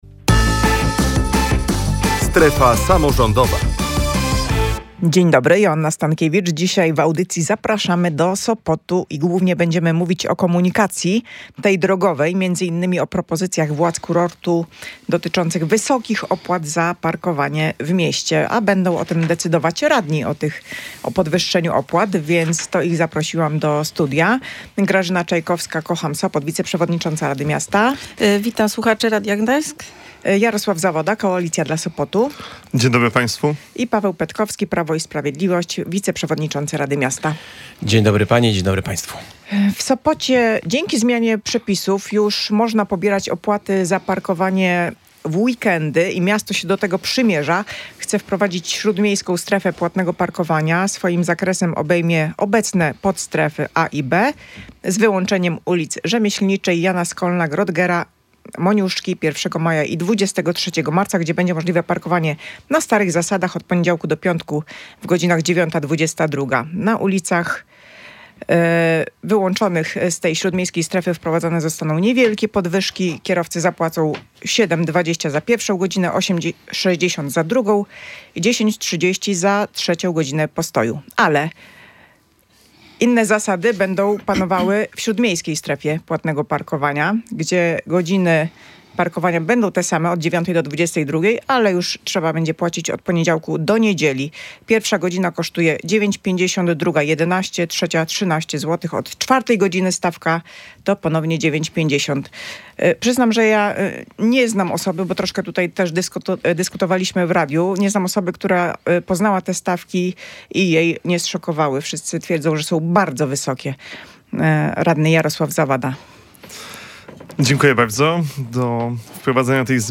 W „Strefie Samorządowej” dyskutowali o tym miejscy radni.